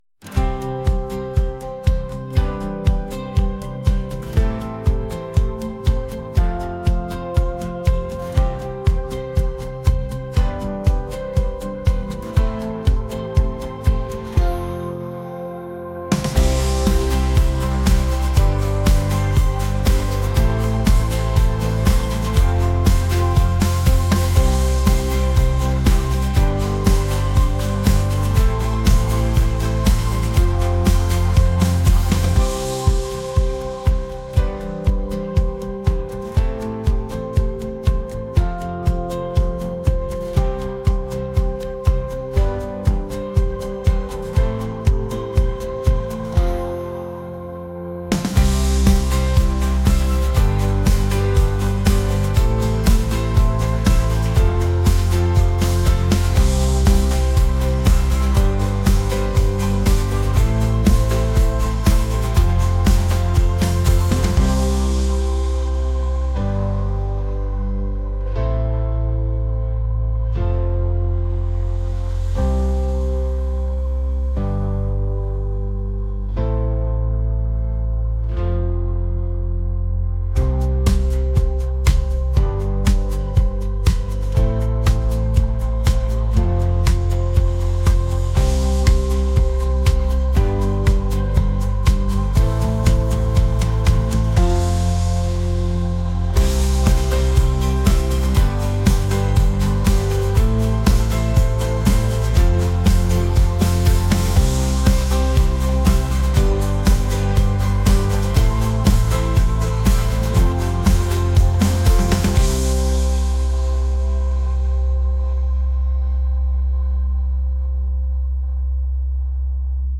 indie | pop | laid-back